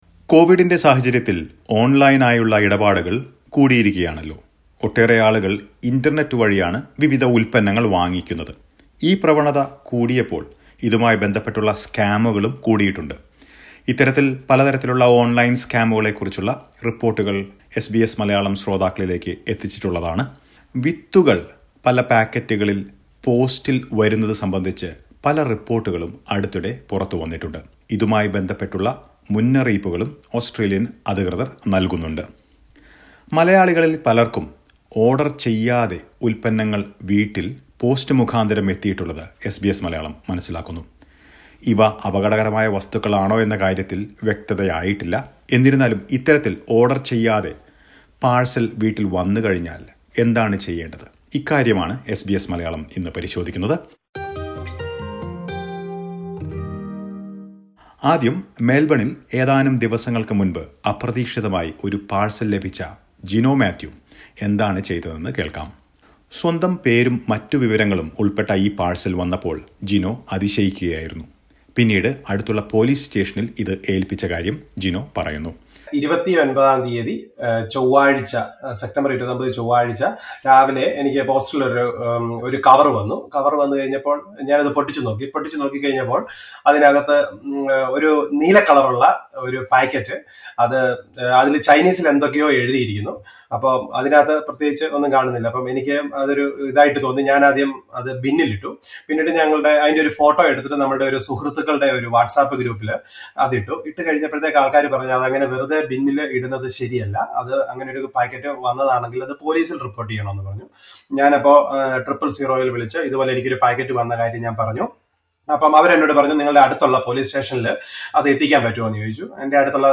Many have reported receiving unsolicited parcels recently. Authorities say many of these contained seeds and they should be reported. Listen to a report.